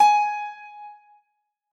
piano-sounds-dev
gs5.mp3